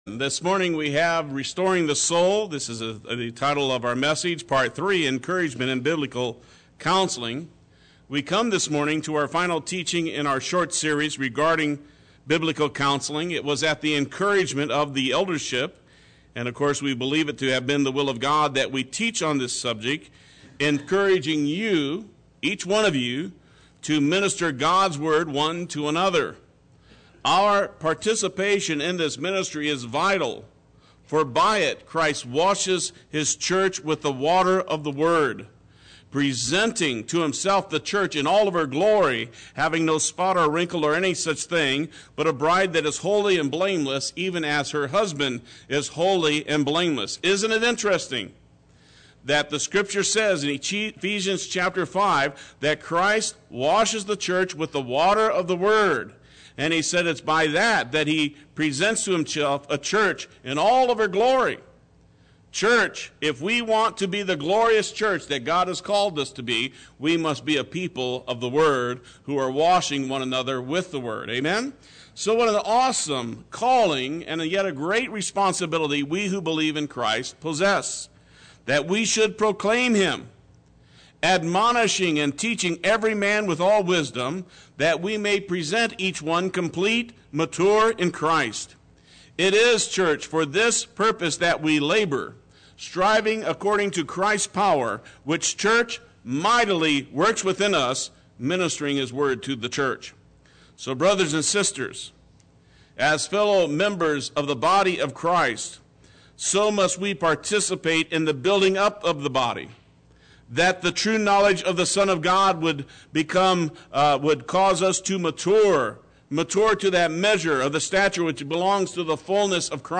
Play Sermon Get HCF Teaching Automatically.
Part III Sunday Worship